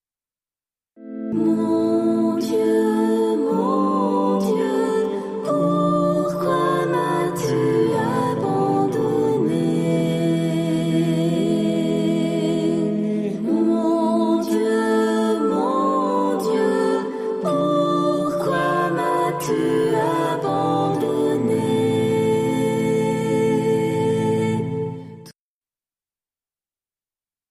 RefrainPsaume.mp3